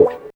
137 GTR 2 -L.wav